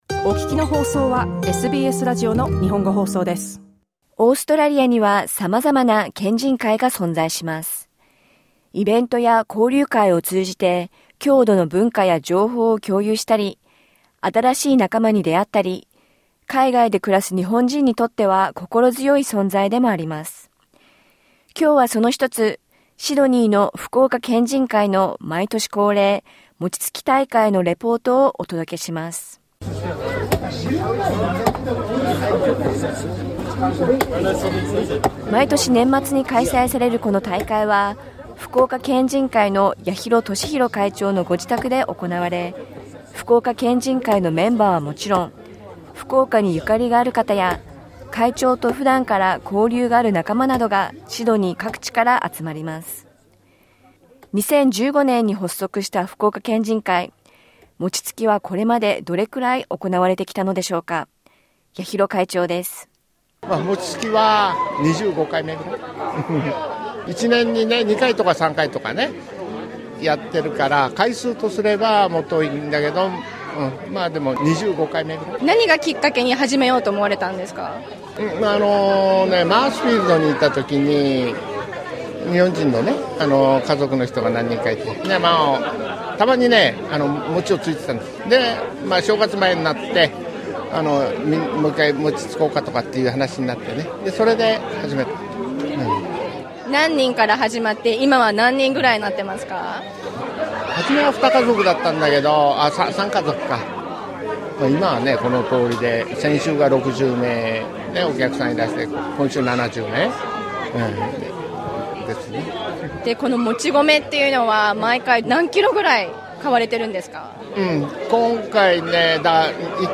シドニー福岡県人会の毎年恒例、餅つき大会にお邪魔しました。つきたてのお餅はあんこを入れたり、きな粉をかけたり、福岡のお雑煮として振る舞われました。